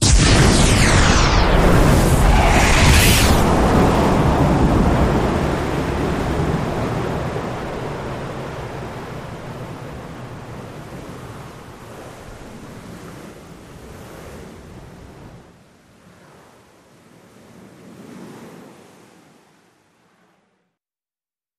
Depth Charge
Explosion, Surface Perspective Depth Charge Multiple Ver. 2